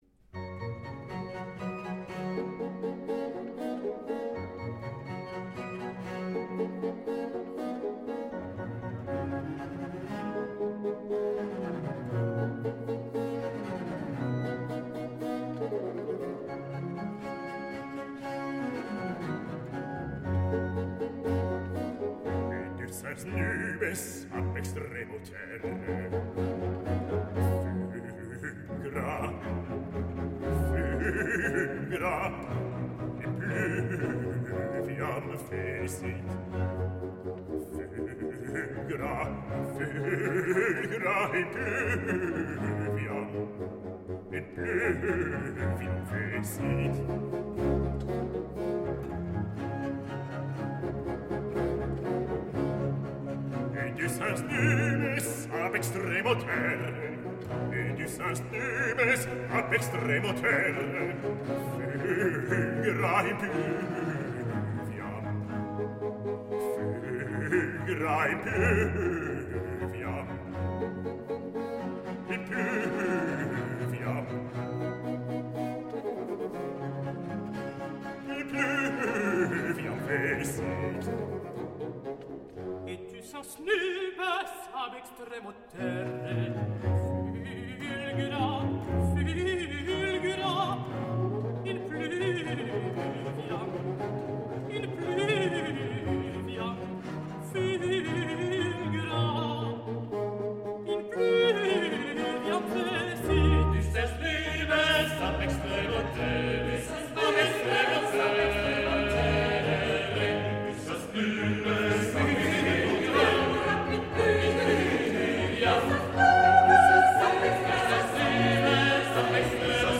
Grand motet
Prelude - Recit de Basse et de Haute-Contre - Choeur